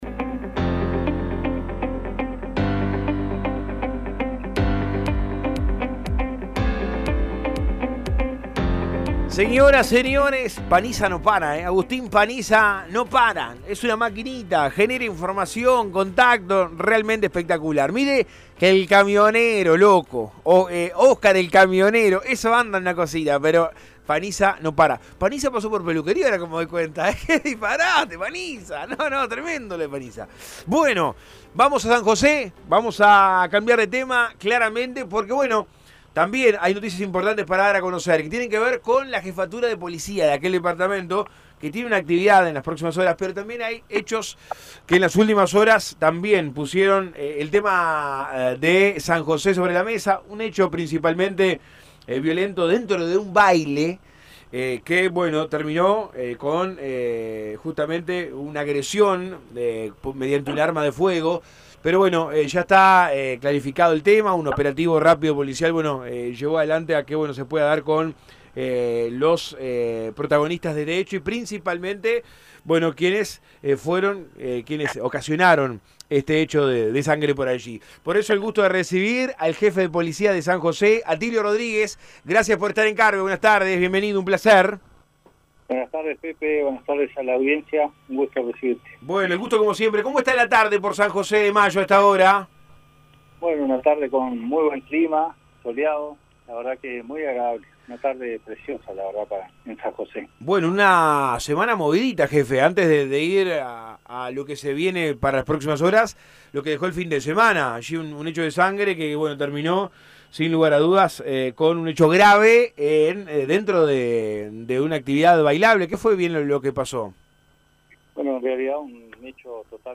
El Centro de Comando Unificado instalado en la Jefatura de San José es el más grande del interior del país; permitirá monitorear en tiempo real 492 cámaras colocadas en todo el departamento. En Todo Un País habló sobre este tema el jefe de policía departamental Atilio Rodríguez. También se refirió al hecho de violencia que se dio el pasado fin de semana en un boliche en la capital departamental.